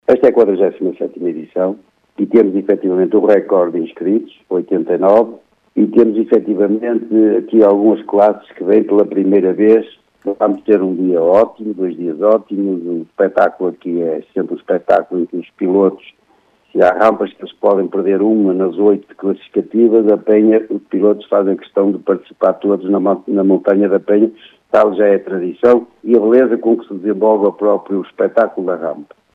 Declarações